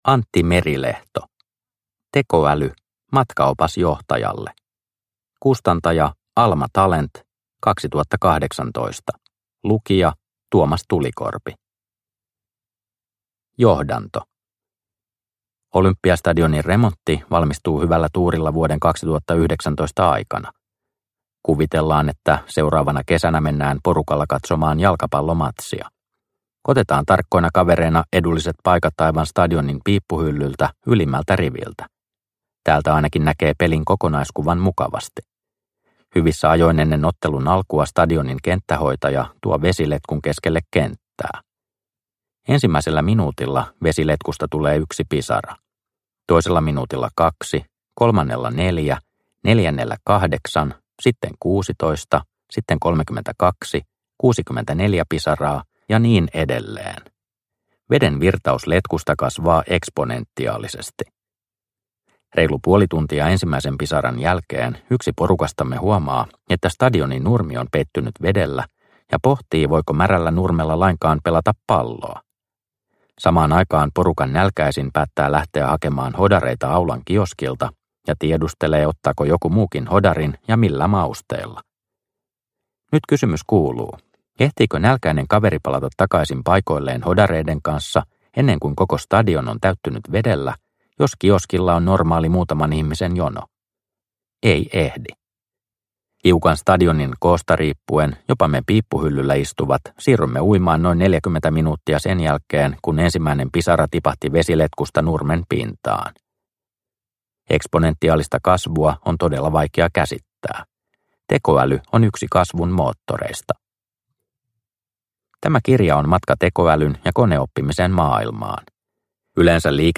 Tekoäly – Ljudbok – Laddas ner